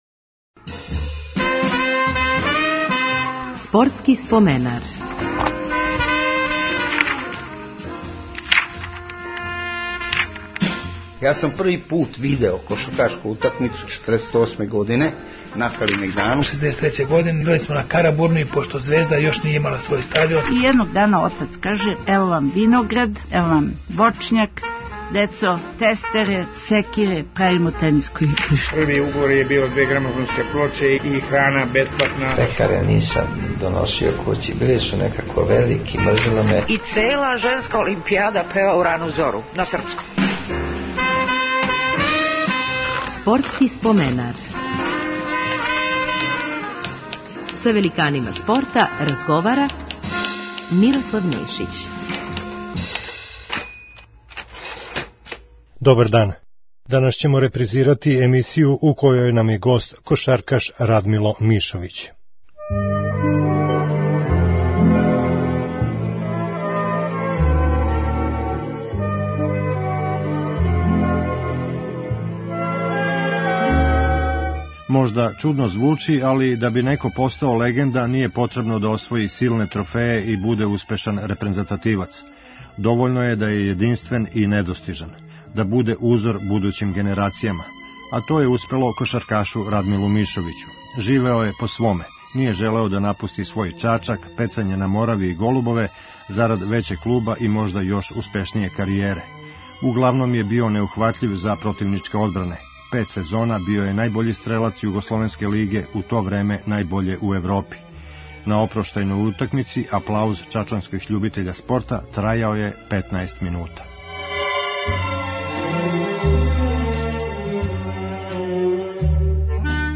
Репризирамо емисију у којој нам је гост кошаркаш Радмило Мишовић. Остао је упамћен као један од најбољих кошгетера свог доба.